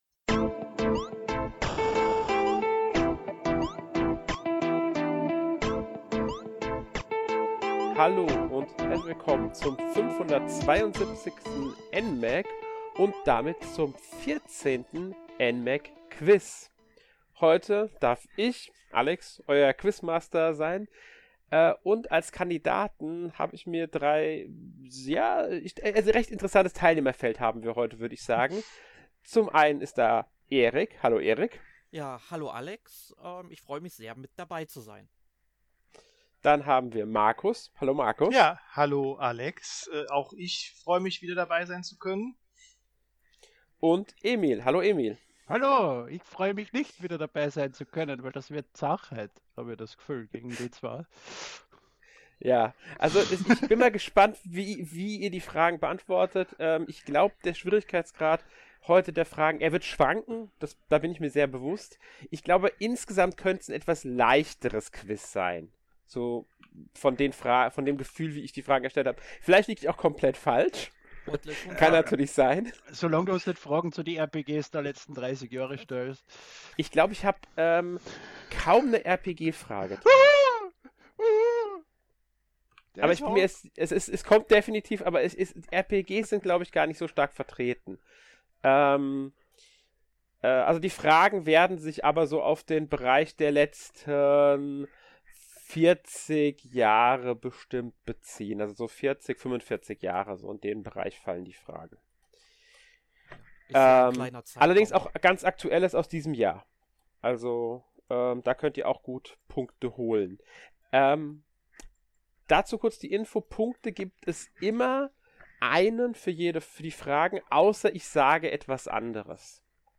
Kurz vor Jahresende haben sich drei mutige NMag-Redakteure kniffligen Fragen im vierzehnten NMag-Quiz gestellt.